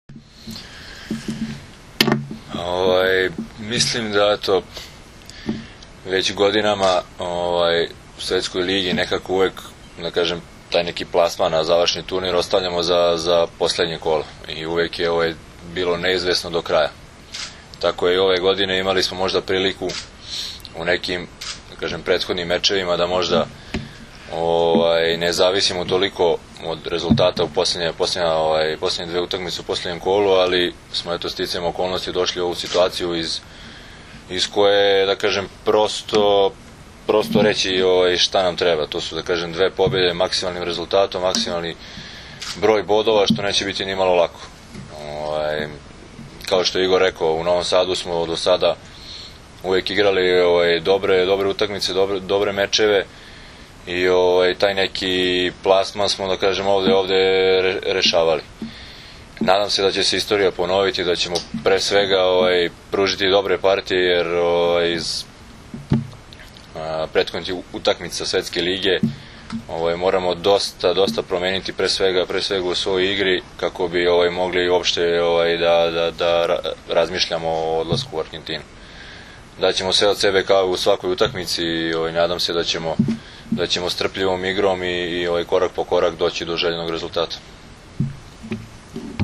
U hotelu Park u Novom Sadu danas je održana konferencija za novinare uoči utakmica poslednjeg, V V vikenda B grupe XXIV Svetske lige 2013. između Srbije i Italije.
IZJAVA DRAGANA STANKOVIĆA